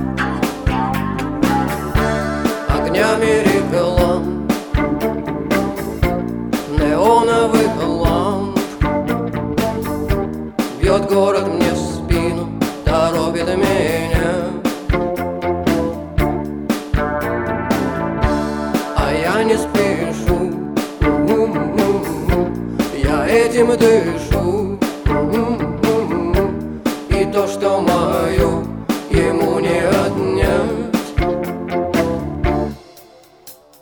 атмосферные